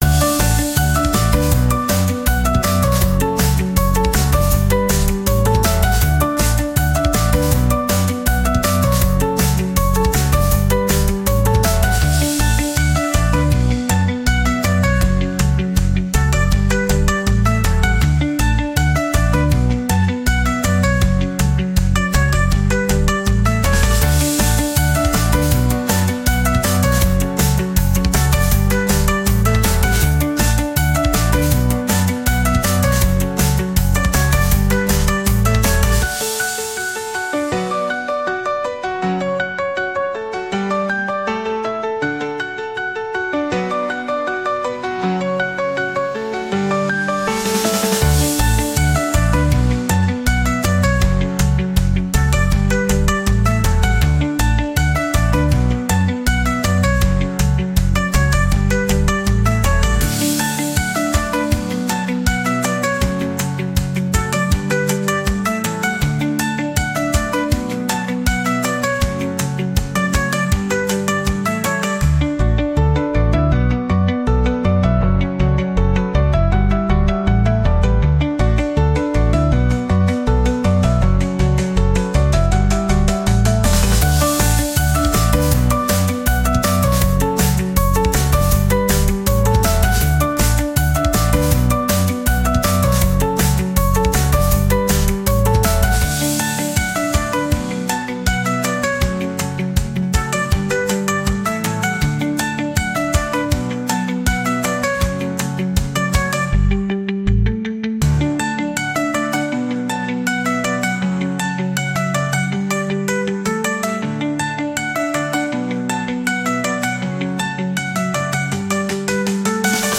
• Категория: Детские песни / Музыка детям 🎵